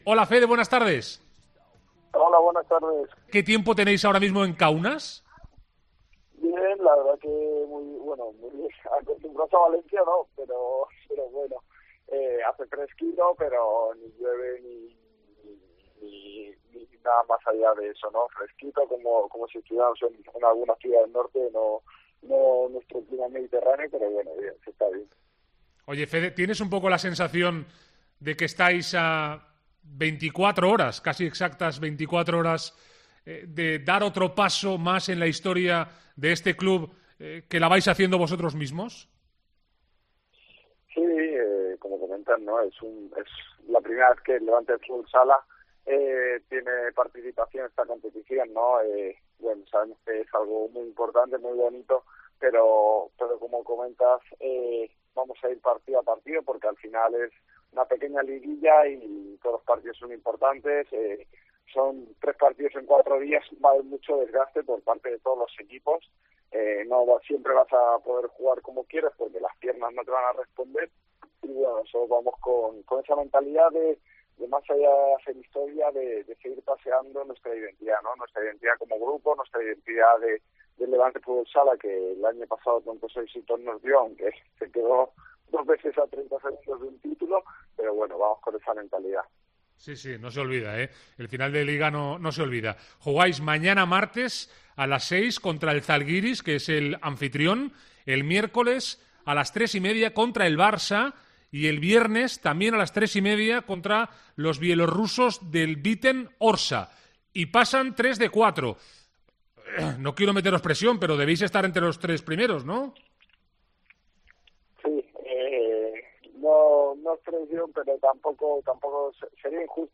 ENTREVISTA COPE